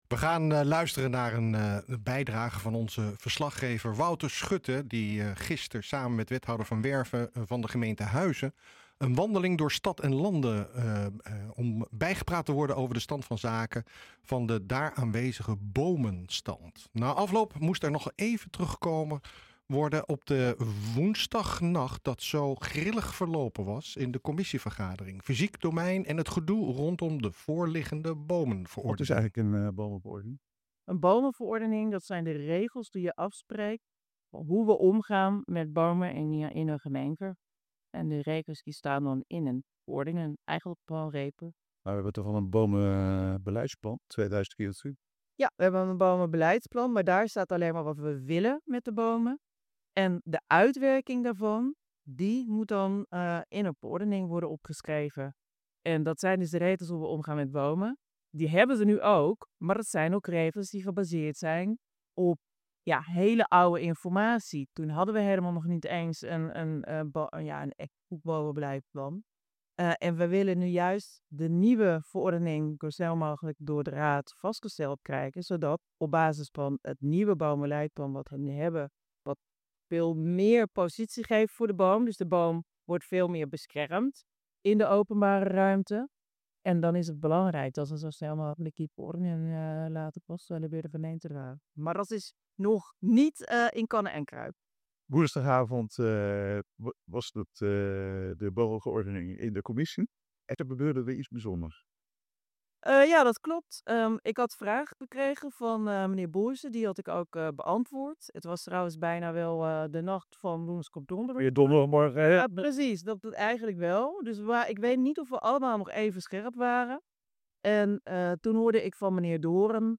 een wandeling door Stad&Lande om bijgepraat te worden over de stand van zaken van het daar aanwezige bomenbestand.